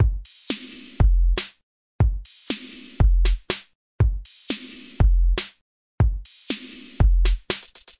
free loops/beats/sequences/patterns/synthesizersounds/percussionloops/thingers " hi hat loop 120bpm
描述：帽子循环120 bpm
标签： 狂野 循环 高科技 120BPM 免费-beat 120-BPM 电子乐 120 自由 俱乐部 最小 房子 配音 精神恍惚 自由循环 循环的dubstep 节拍 毛刺 BPM 舞蹈 无环
声道立体声